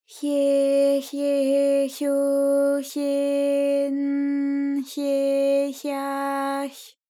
ALYS-DB-001-JPN - First Japanese UTAU vocal library of ALYS.
hye_hye_hyo_hye_n_hye_hya_hy.wav